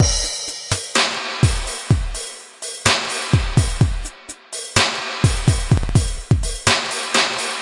Drum Loops " Dubstep Beat
Tag: 电子乐 60BPM 配音 房子